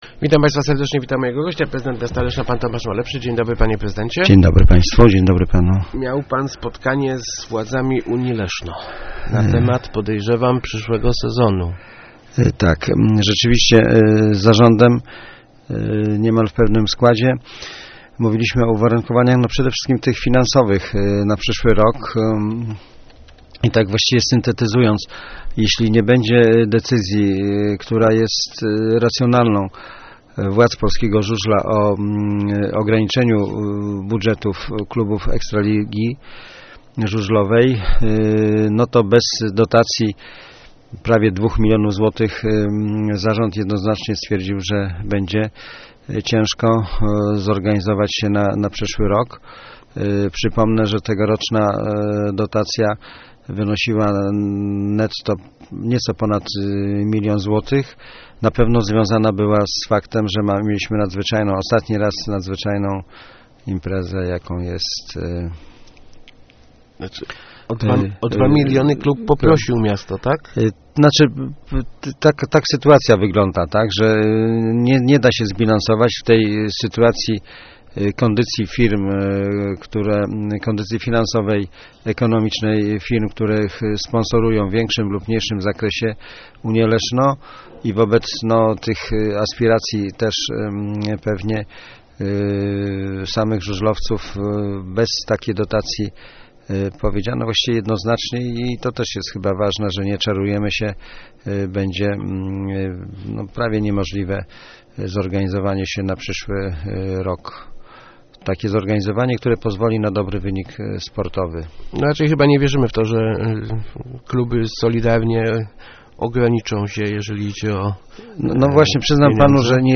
Gościem Kwadransa Samorządowego był prezydent Tomasz Malepszy. ...